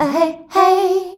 AHEHEY  D.wav